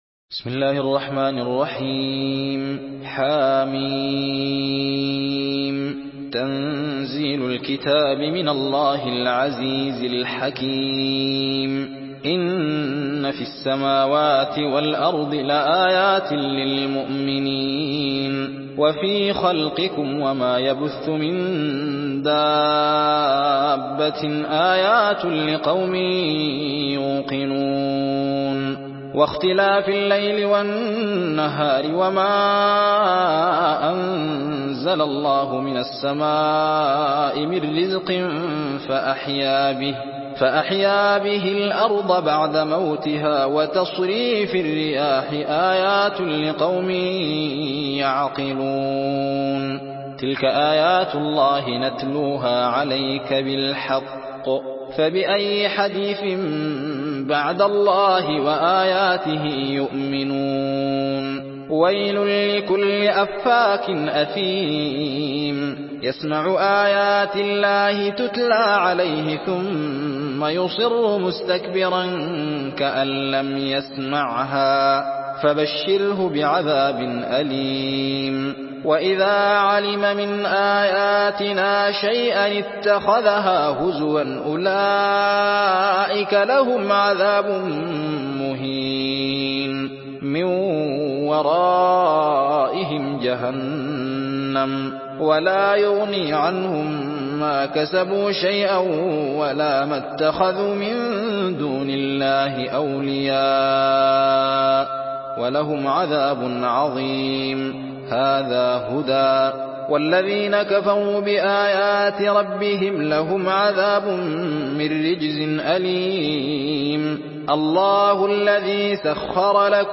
تحميل سورة الجاثية بصوت الزين محمد أحمد
مرتل حفص عن عاصم